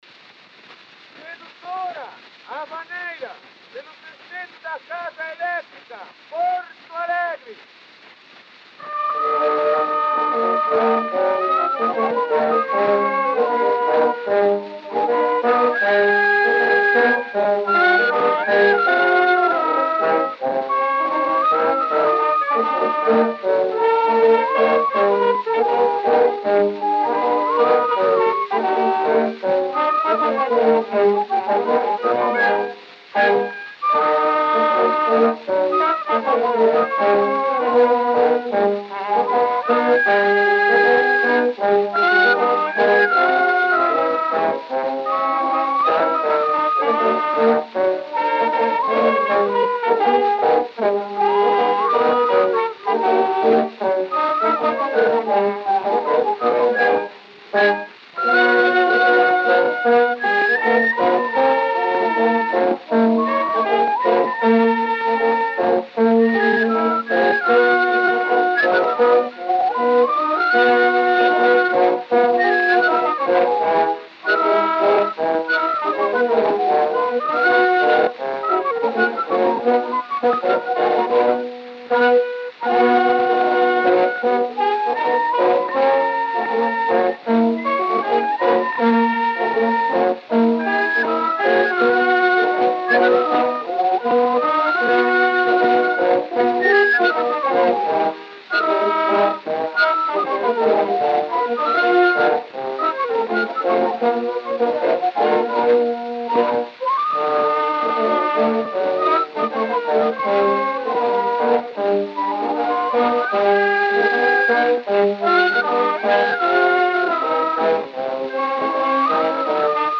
O gênero musical foi descrito como "Havaneira".